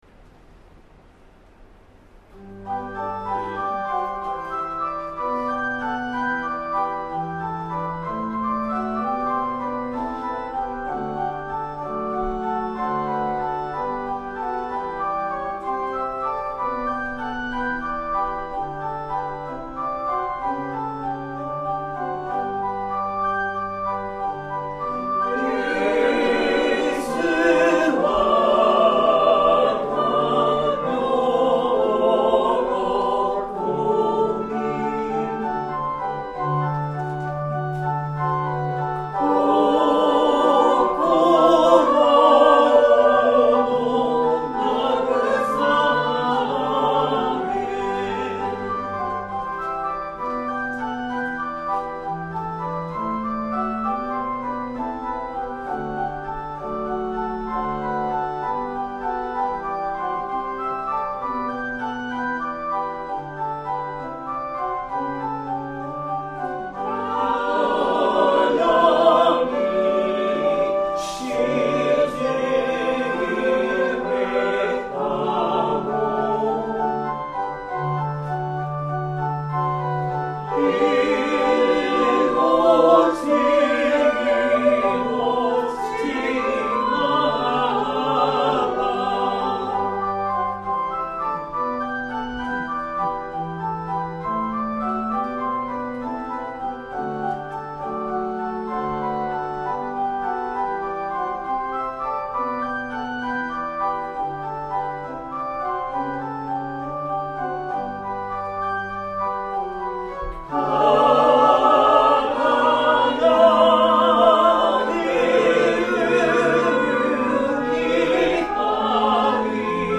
♪聖歌隊練習補助音源
Tonality = G　Pitch = 440　Temperament =Equal
1　 Organ そのまま none